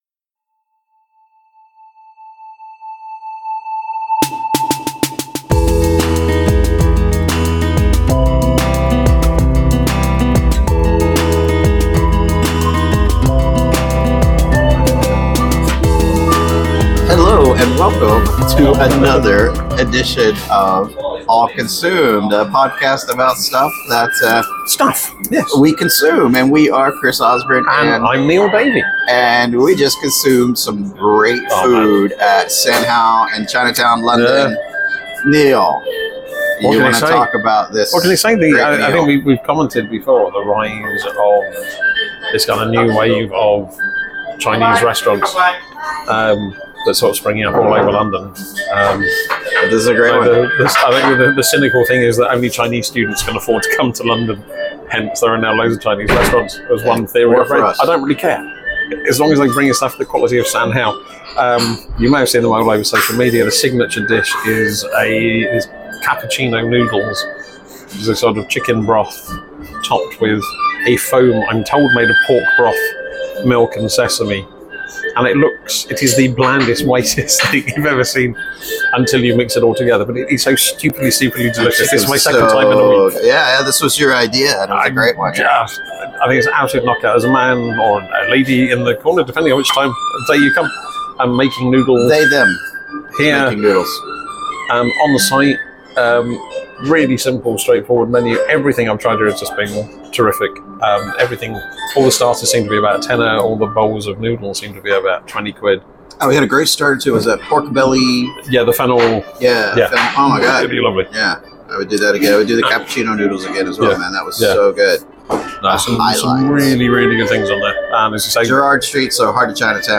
Recorded live at the Bond Street branch of Japanese curry house, Coco Ichiban.